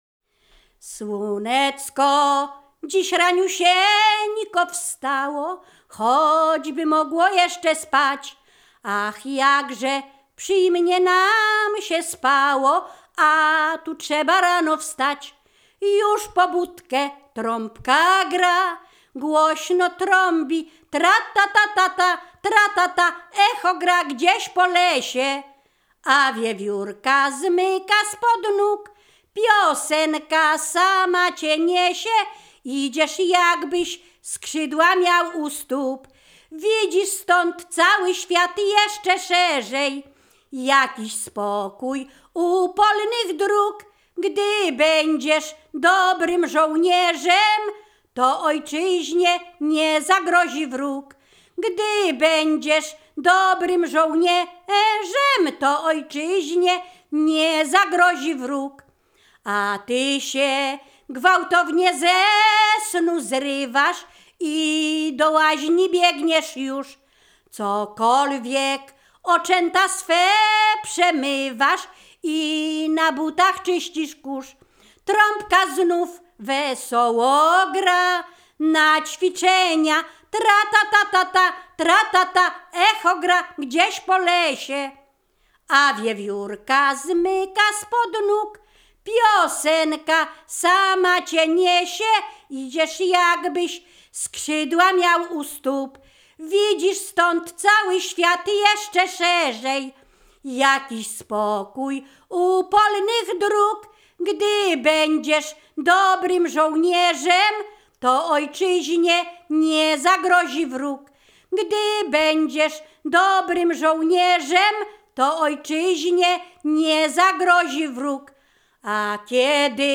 Ziemia Radomska
województwo mazowieckie, powiat przysuski, gmina Rusinów, wieś Brogowa